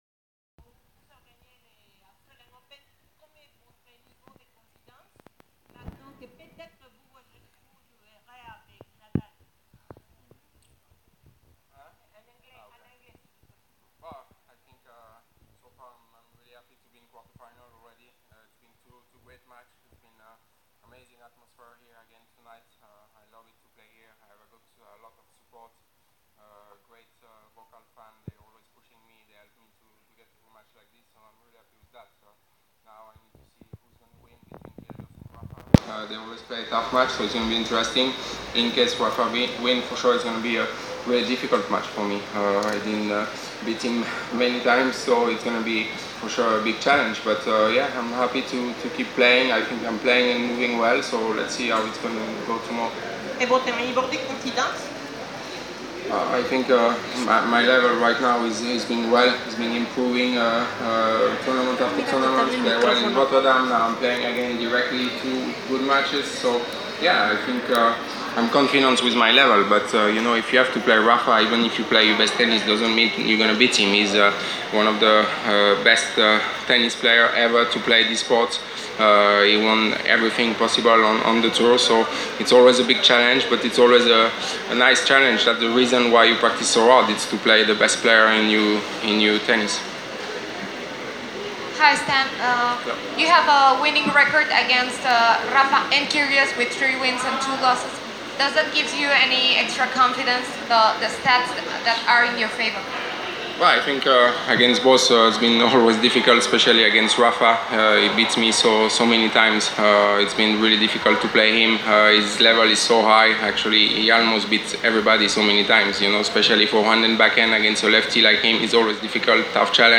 Press Conference – Stan Wawrinka (27/02/2019)